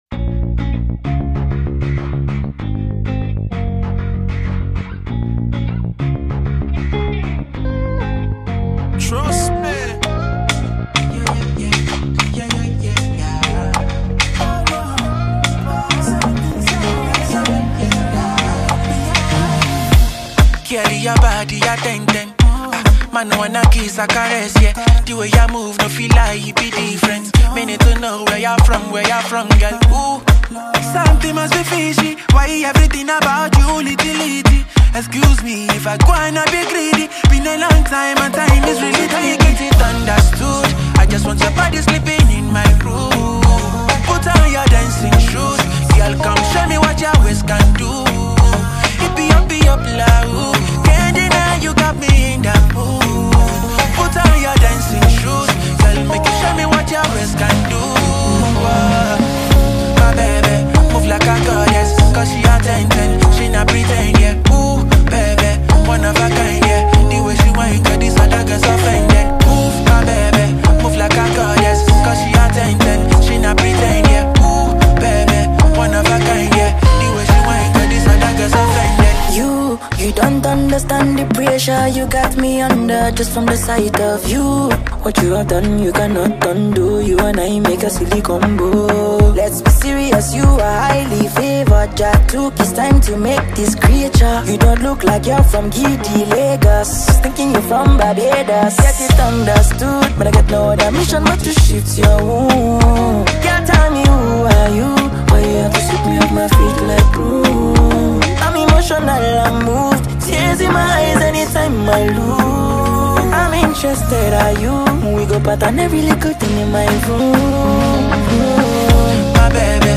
American singer-songwriter